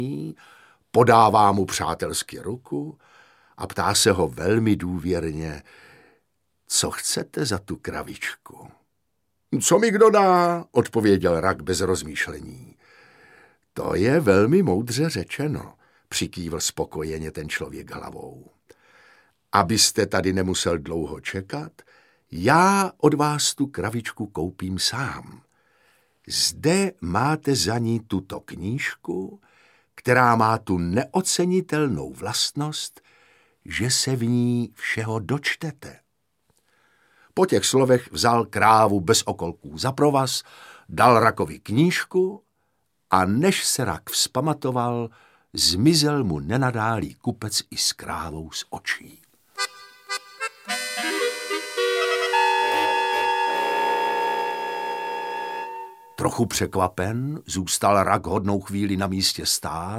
Audiobook
Read: Otakar Brousek st.